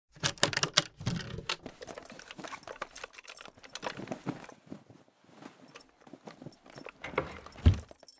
chest_0.ogg